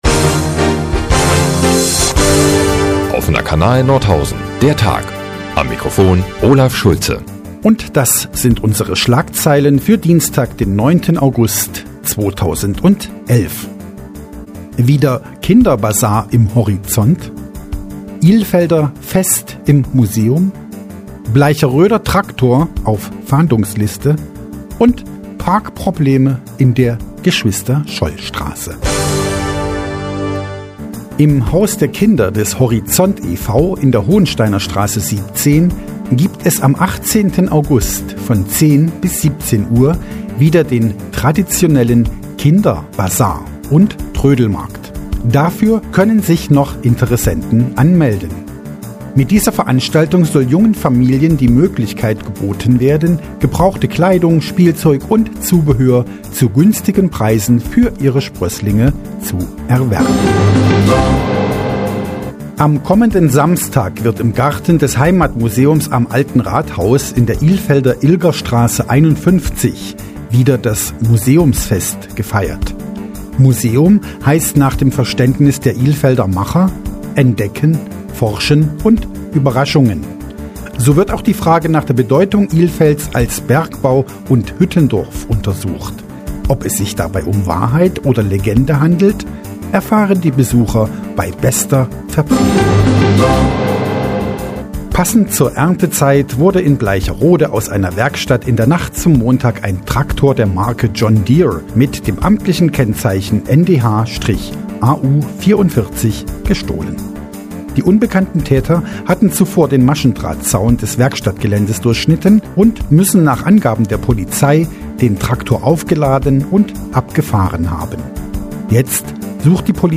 Die tägliche Nachrichtensendung des OKN ist nun auch in der nnz zu hören. Heute mit einer speziellen Parkhilfe, einem verletzten Traktor, neugierigen Ilfeldern und einem "Kinderbasar"...